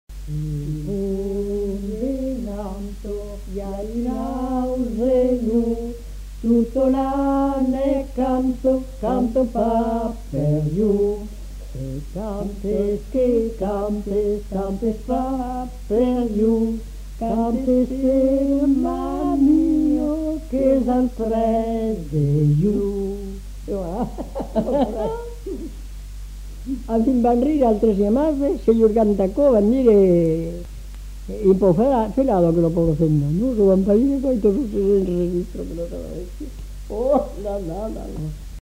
Aire culturelle : Haut-Agenais
Lieu : Cancon
Genre : chant
Effectif : 2
Type de voix : voix de femme
Production du son : chanté
Description de l'item : fragment ; 1 c. ; refr.